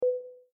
dialog-warning.mp3